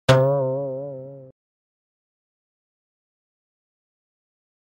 Звуки пружины
На этой странице собраны разнообразные звуки пружин: от резких щелчков до плавного скрипа.
Звук растягивания и освобождения пружины